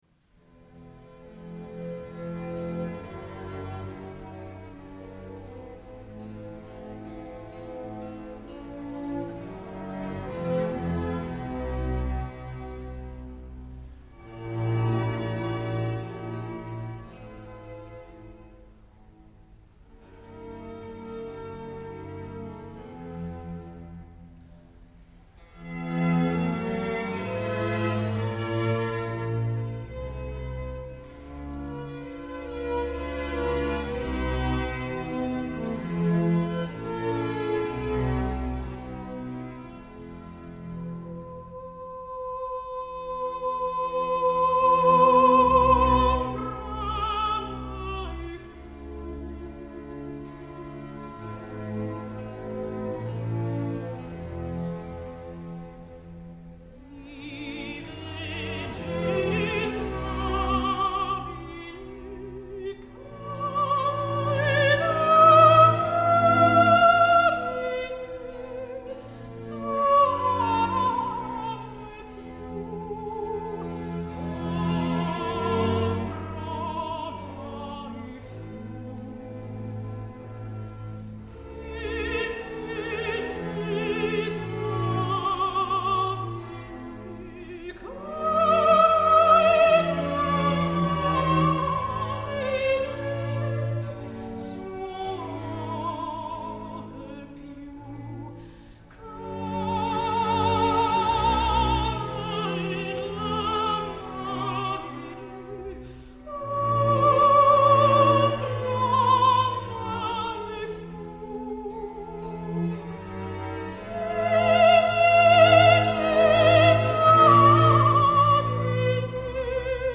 Doncs si, tinc “DiDonatitis”, que és ni més ni menys un estat d’admiració cap a la mezzo americana que aquests dies està fent aixecar el públic del Liceu, després de cantar una Angelina de La Cenerentola absolutament fascinant.
Us deixo dos trossets d’un concert que va fer el passat 30 de novembre (el mateix dia que el Liceu és rendia davant de Juan Diego Flórez) al Théâtre des Champs-Elisées de Paris amb l’Ensemble Matheus dirigit per Jean-Christophe Spinosi.